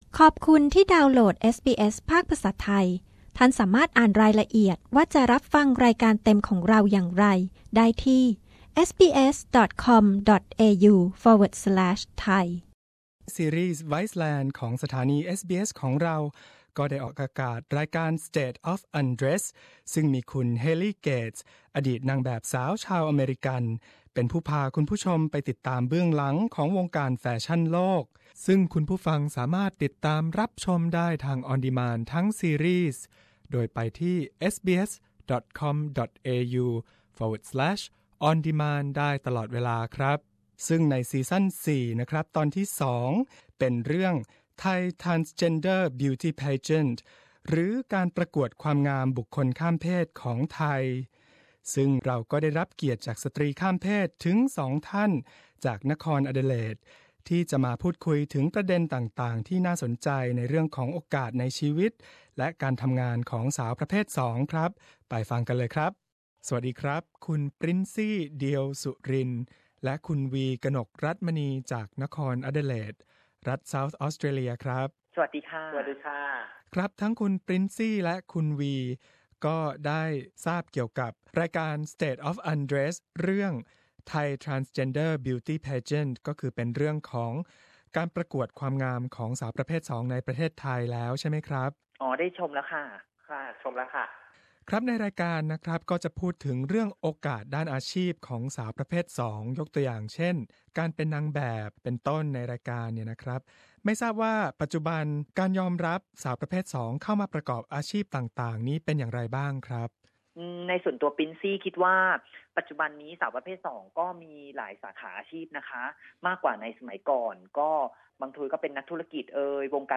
สตรีข้ามเพศชาวไทยสองท่านจากนครแอดิเลด พูดคุยการก้าวข้ามปราการอาชีพและชีวิต ที่ประเทศออสเตรเลียในปัจจุบัน และเรื่องราวจากรายการ สเตท ออฟ อันเดรส ซึ่งเป็นซีรีส์ของ เอสบีเอส ไวซ์แลนด์ ที่ไปติดตามสตรีข้ามเพศในไทย